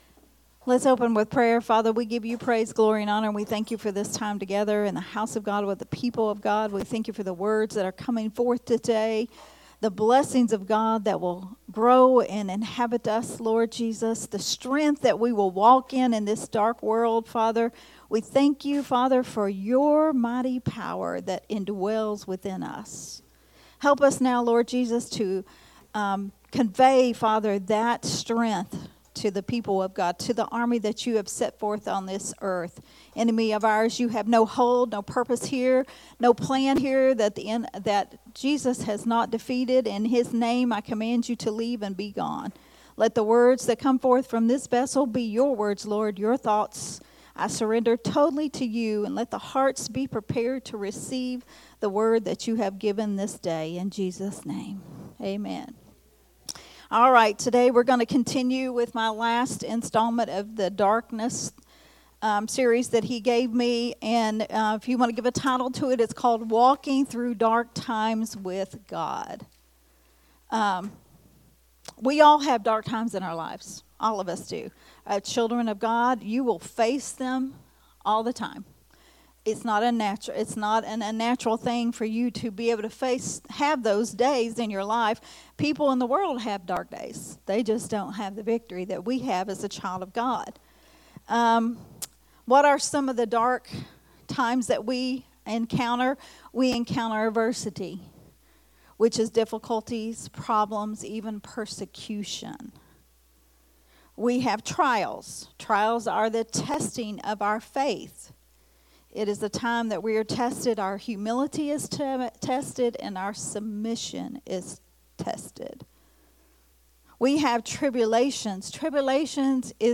a Sunday Morning Risen Life Teaching
recorded at Unity Worship Center on July 7